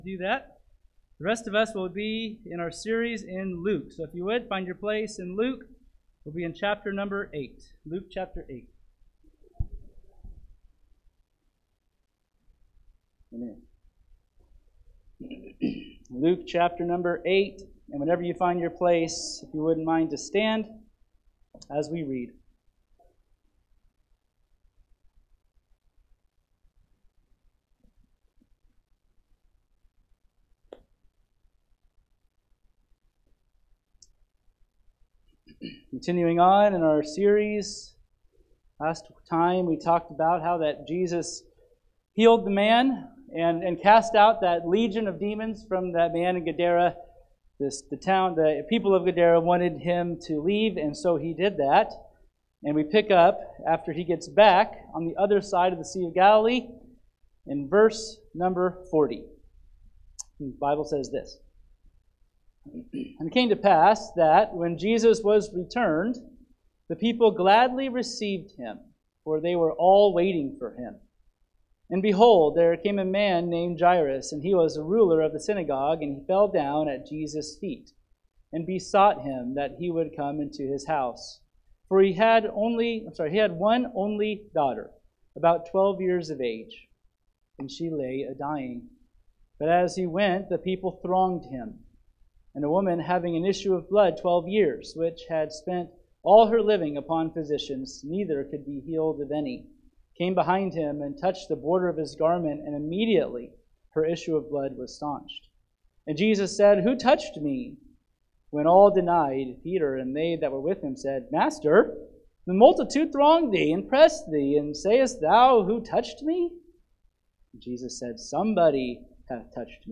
Luke 8:40-56 – Jesus Always Has Time For You – Valley Avenue Baptist Church – Falls City, NE